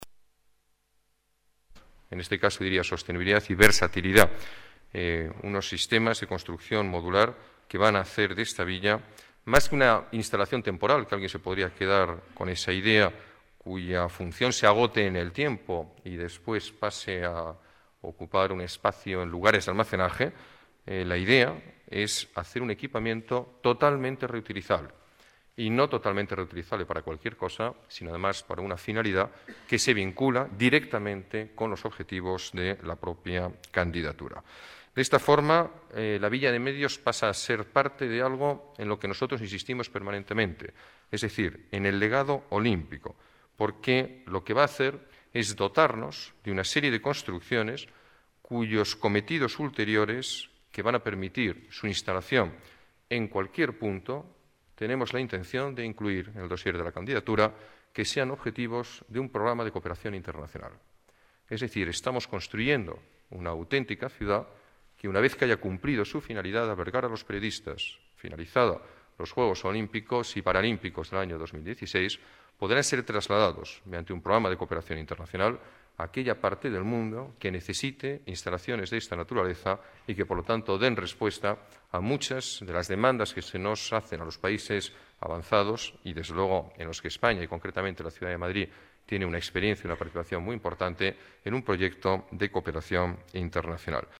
Nueva ventana:Declaraciones del alcalde de Madrid, Alberto Ruiz-Gallardón